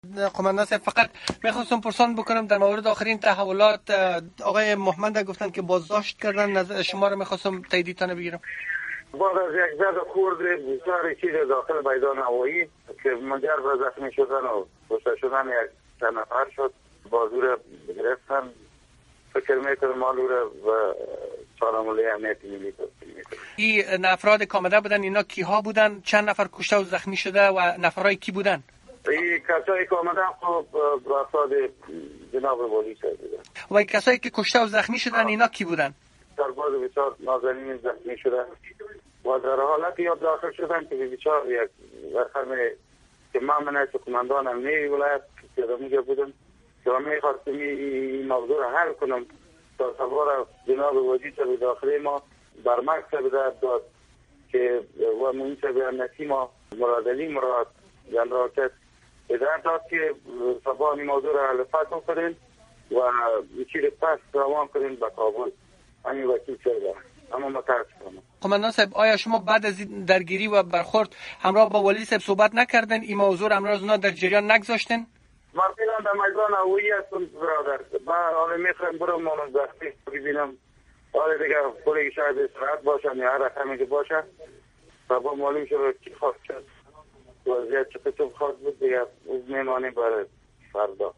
صحبت با کمال سادات، فرماندۀ امنیۀ ولایت بلخ را ازینجا شنیده می توانید: